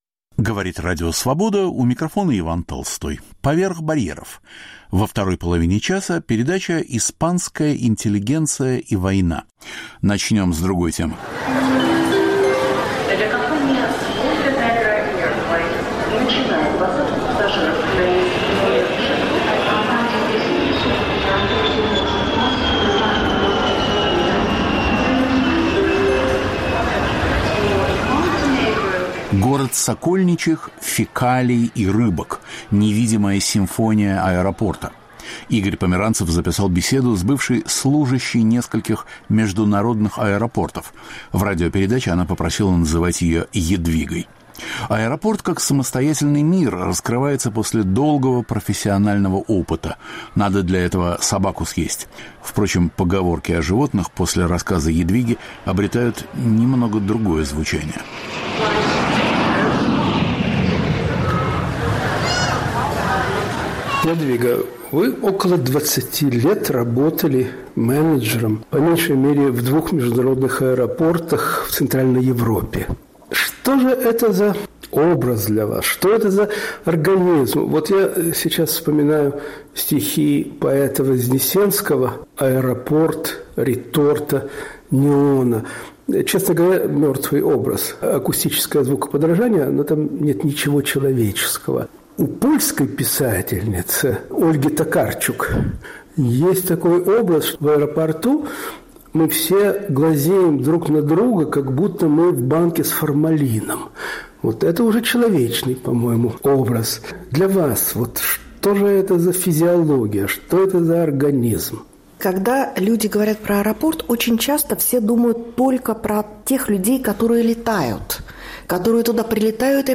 1 ч. Аэропорт, его видимые и невидимые стороны, маленькие тайны в рассказе эксперта. 2 ч. Интеллигенция и война.